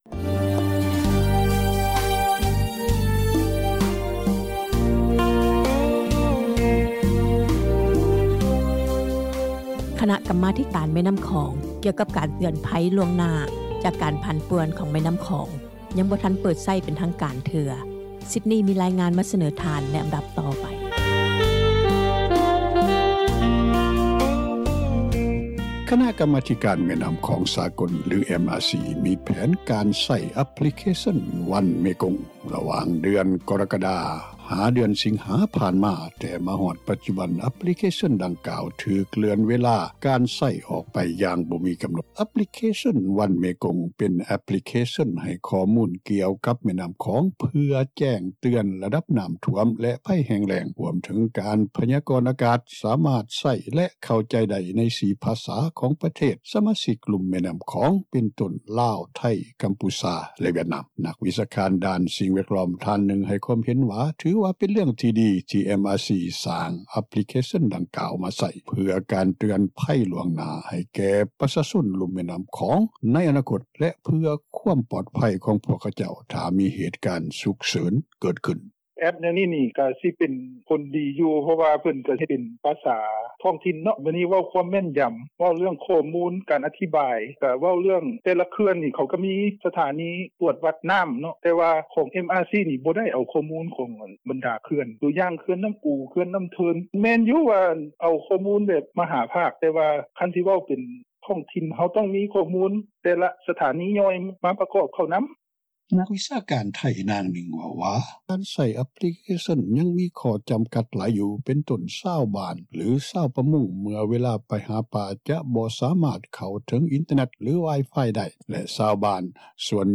ນັກວິຊາການໄທ ນາງໜຶ່ງເວົ້າວ່າ  ການໃຊ້ ແອັບພລິເຄຊັນ ຍັງມີຂໍ້ຈຳກັດຫຼາຍຢູ່ ເປັນຕົ້ນ ຊາວບ້ານຫຼືຊາວປະມົງ ເມື່ອເວລາໄປຫາປາ ຈະບໍ່ສາມາດເຂົ້າເຖິງອິນເຕີແນັດ ຫຼື ວາຍຟາຍ (Wi-Fi) ໄດ້ ແລະຊາວບ້ານສ່ວນໃຫຍ່ ກໍບໍ່ສາມາດເຂົ້າເຖິງອິນເຕີແນັດ ເພື່ອຊອກຫາ ຂໍ້ມູນຈາກແອບປາລິເຄຊັນ ດັ່ງກ່າວ ບາງຄົນບໍ່ມີຮອດ ໂທລະຊັບມືຖື.
ຊາວລາວນາງໜຶ່ງເວົ້າວ່າ ກໍເຫັນດີຖ້າຫາກມີ ແອັບພລິເຄຊັນ ດັ່ງກ່າວ ເພື່ອຄວາມປອດໄພຂອງຊາວບ້ານ ໃນເຫດການສຸກເສີນ ດັ່ງນາງເວົ້າວ່າ: